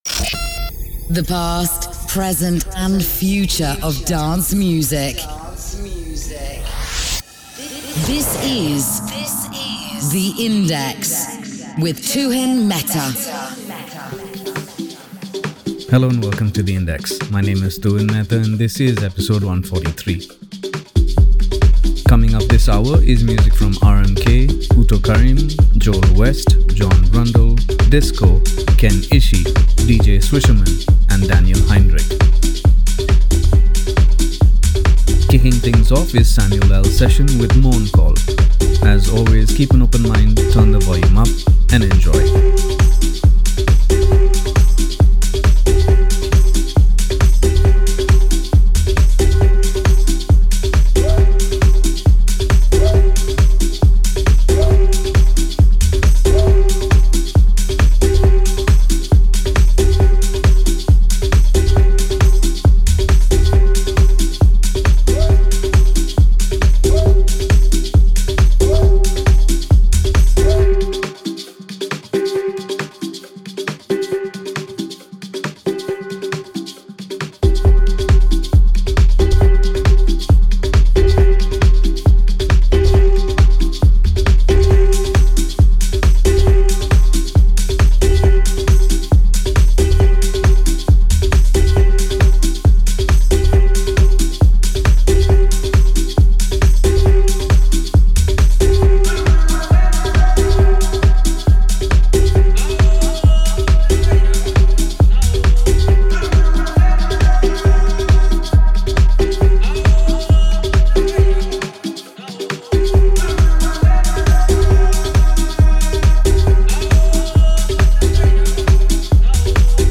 Techno
Dance